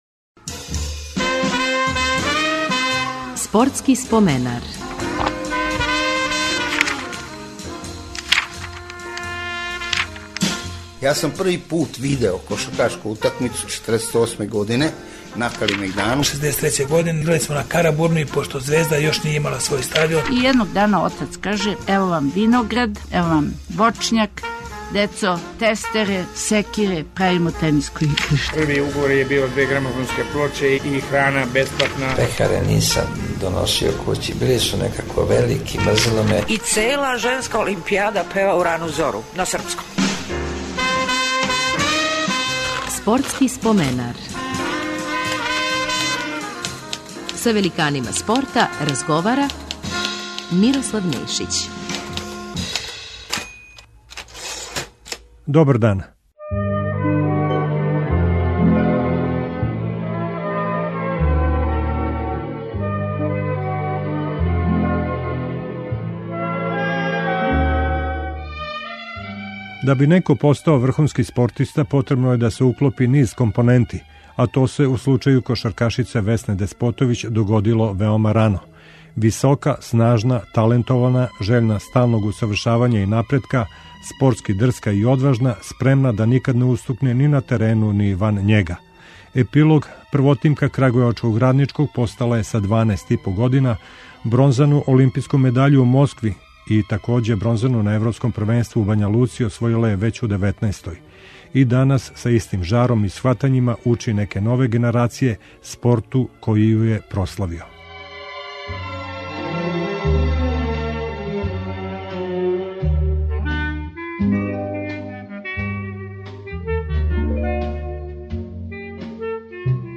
Биће емитовани и снимци из нашег тонског архива сећања актера меча - некадашњег Звездиног голгетера Боре Костића, као и југословенског дипломатског представника у Енглеској Небојше Томашевића, који је преживео удес у Минхену.